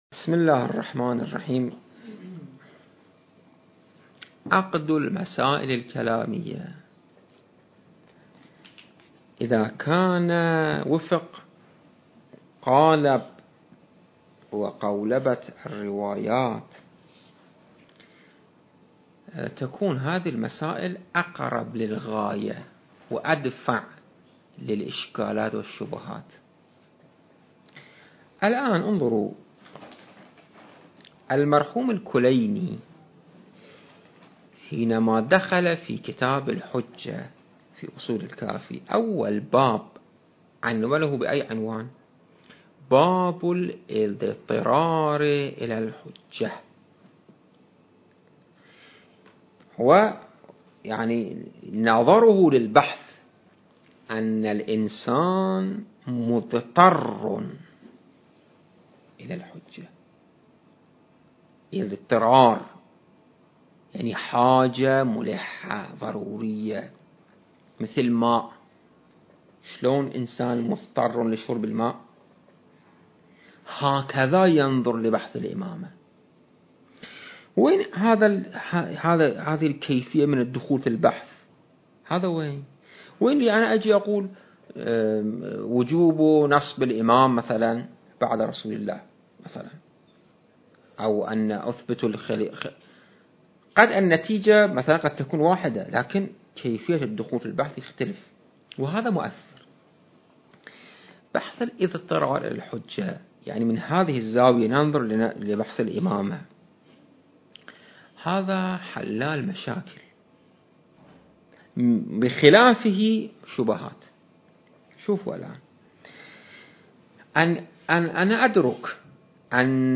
التاريخ: 2021 المكان: معهد المرتضى - النجف الأشرف